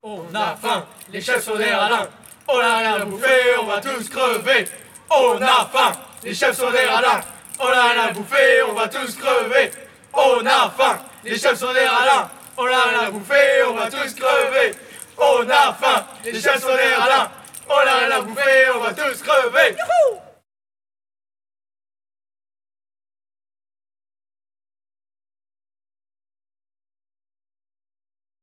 Genre : chant
Type : chant de mouvement de jeunesse
Interprète(s) : Les Scouts de Gilly
Lieu d'enregistrement : Gilly
Chanté avec le repas.